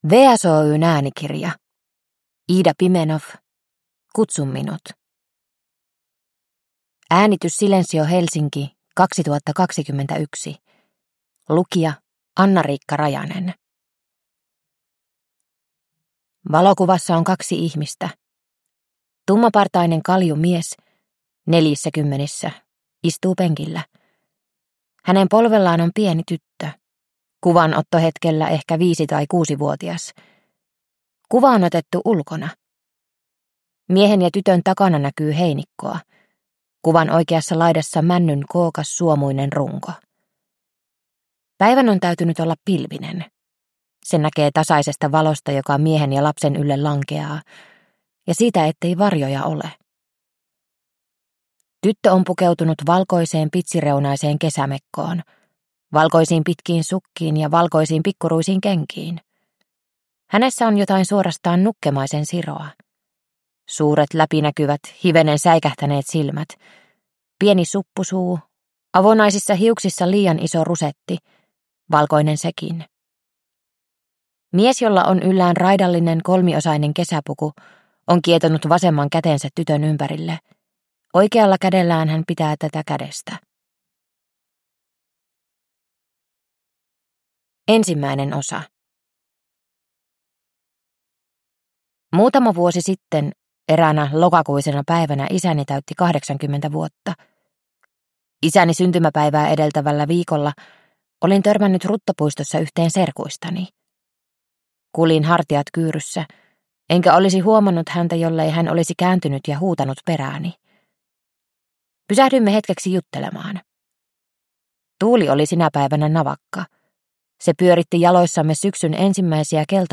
Kutsu minut – Ljudbok – Laddas ner